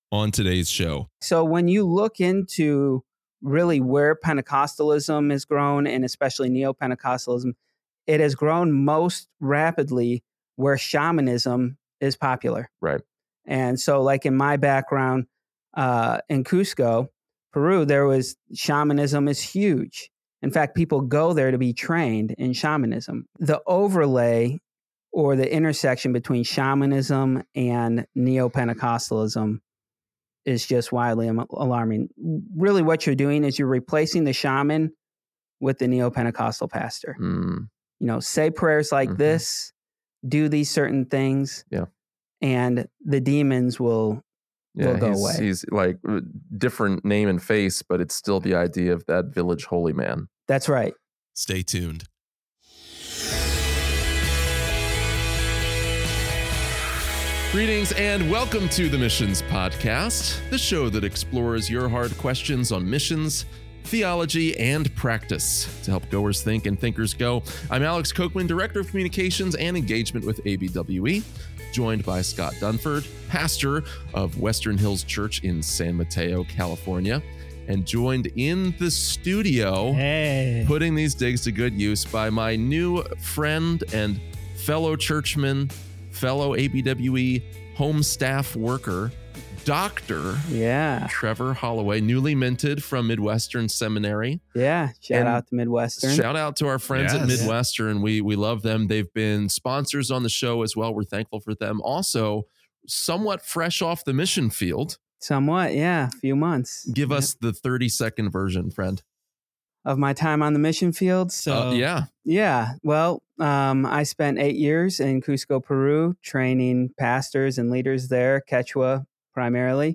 Join us for a thought-provoking conversation that redefines our perspectives on missionary work in a rapidly changing world.